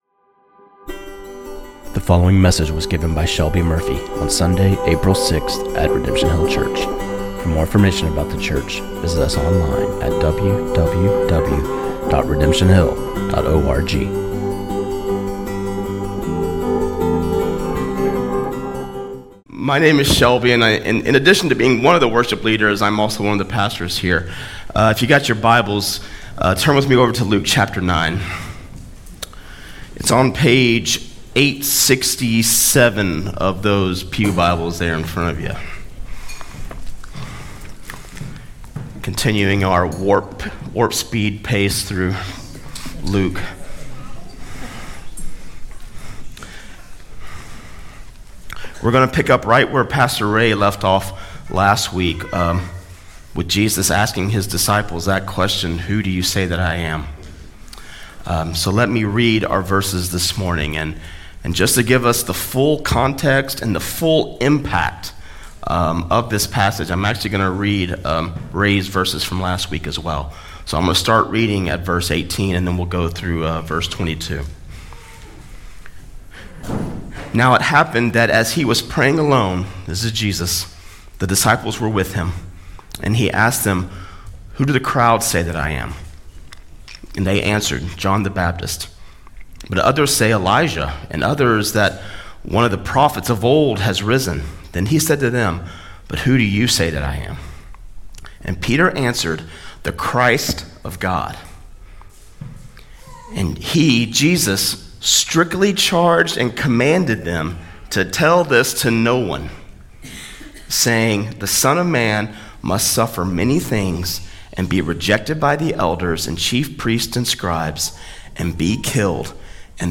This sermon on Luke 9:21-22